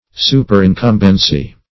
Search Result for " superincumbency" : The Collaborative International Dictionary of English v.0.48: Superincumbence \Su`per*in*cum"bence\, Superincumbency \Su`per*in*cum"ben*cy\, n. The quality or state of being superincumbent.
superincumbency.mp3